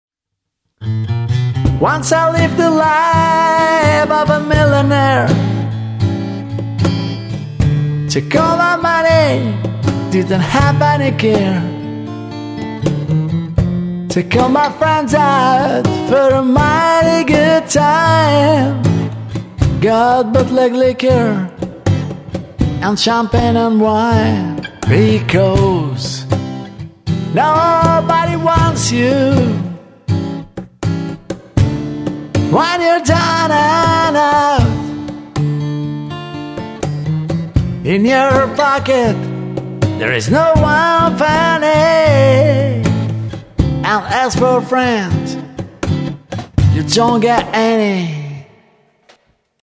Per provare il nostro preamp abbiamo registrato una song con cajon, chitarra acustica e voce (tutti ripresi con il Tube Box ed un microfono Microtech Gefell M940).
Full Mix